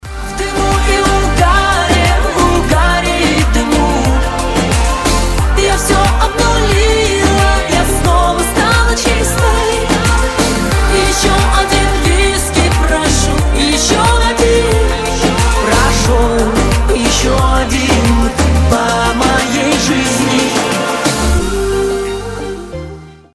• Качество: 320, Stereo
поп
женский вокал